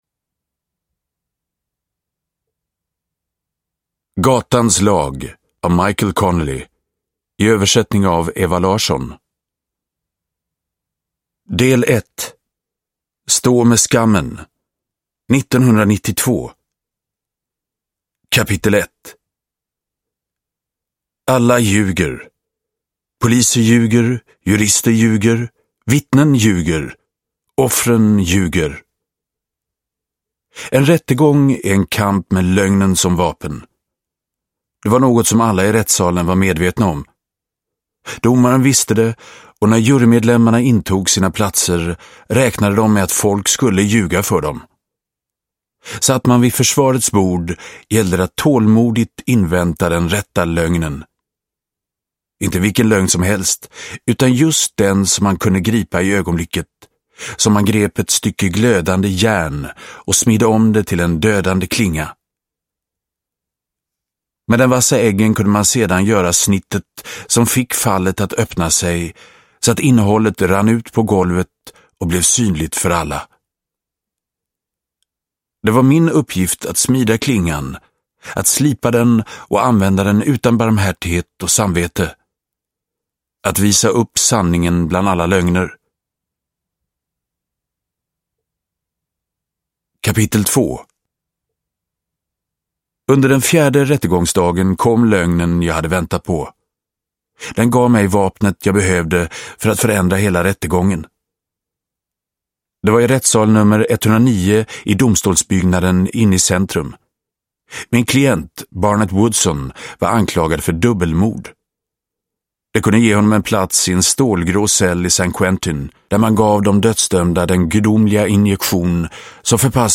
Gatans lag – Ljudbok